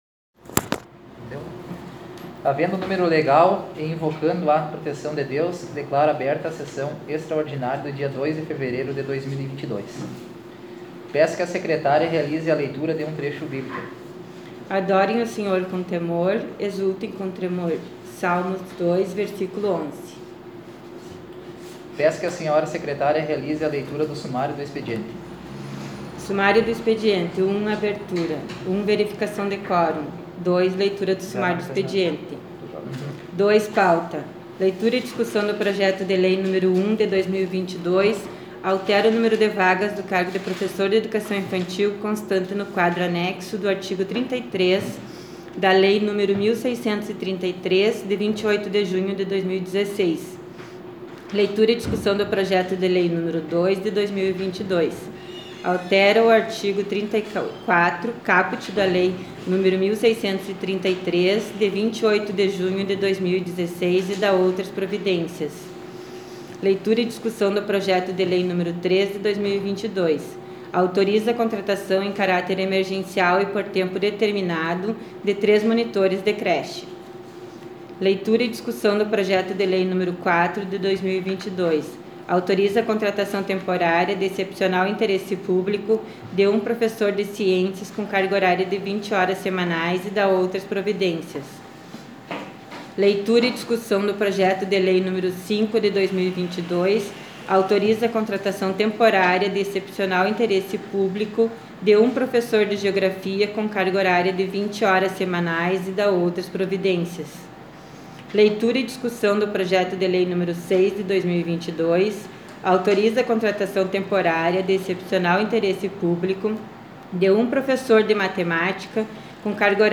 Sessão extraordinária 01/2022